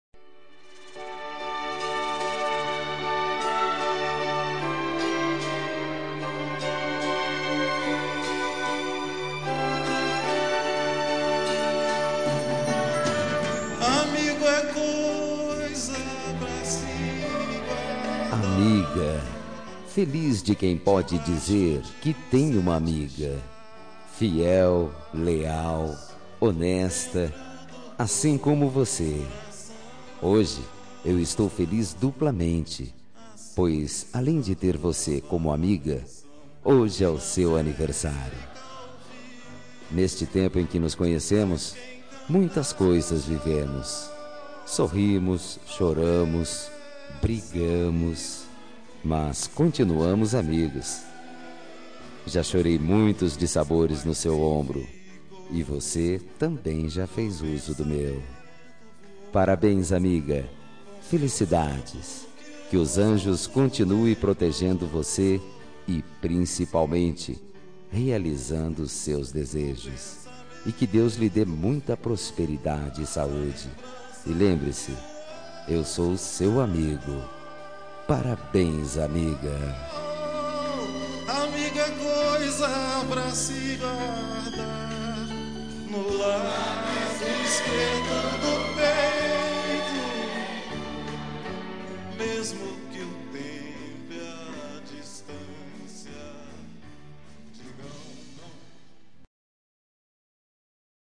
Telemensagem Aniversário de Amiga – Voz Masculina – Cód: 1578